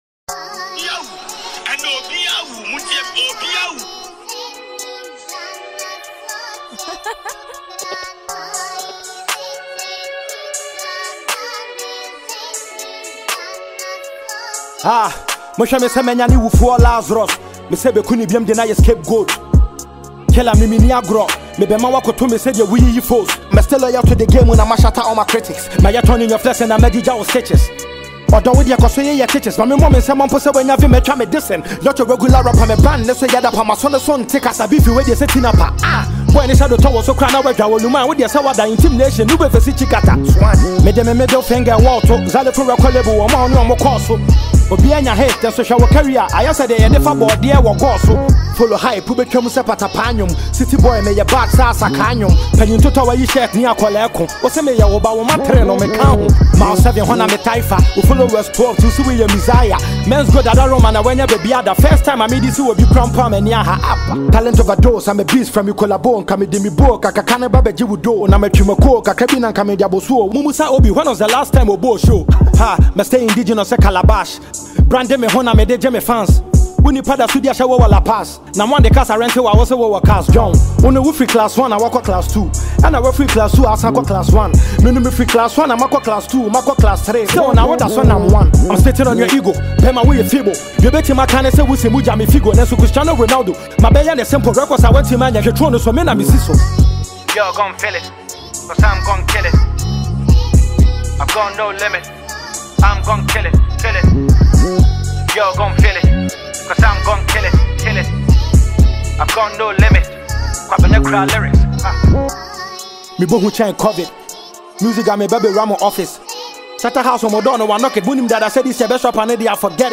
Ghana Music Music
Ghanaian Kumerica Rapper